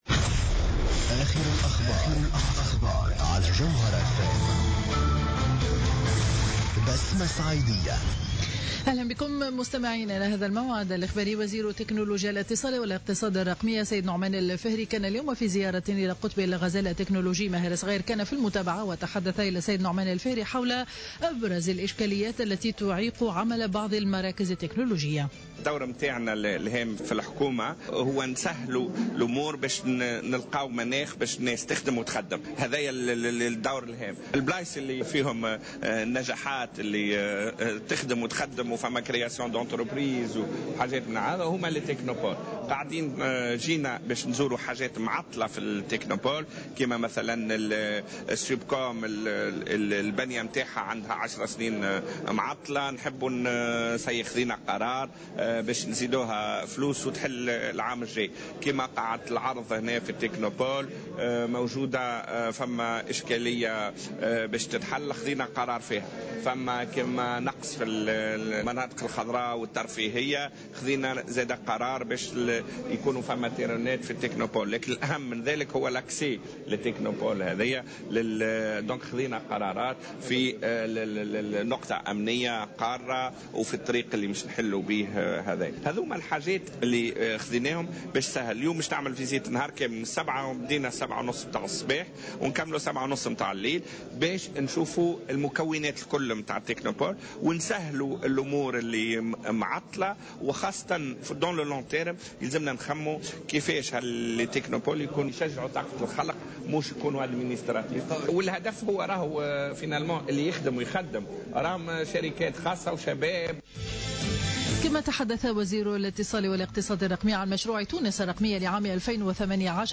نشرة أخبار منتصف النهار ليوم الثلاثاء 17 مارس 2015